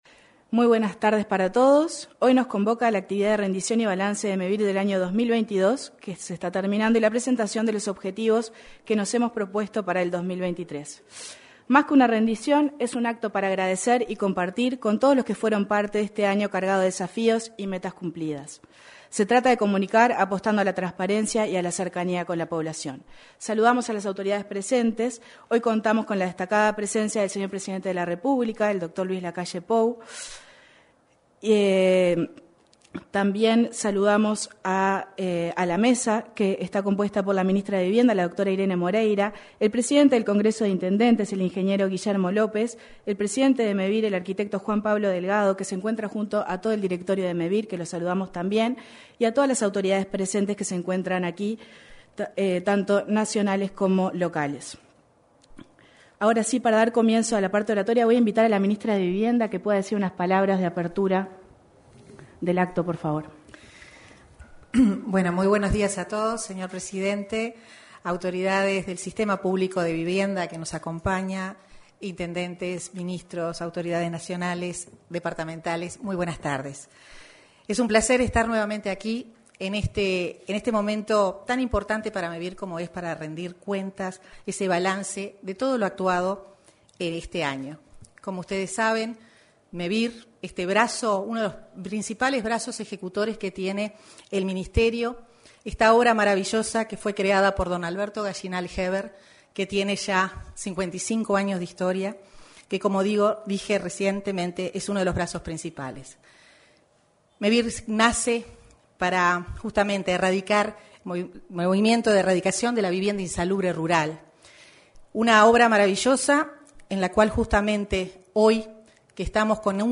Este martes 13, en el salón de actos de la Torre Ejecutiva, se realizó el cierre de año y balance 2022 de Mevir.
En la oportunidad, se expresaron: la ministra de Vivienda, Irene Moreira; el presidente del Congreso de Intendentes, Guillermo López, y el presidente de Mevir, Juan Pablo Delgado.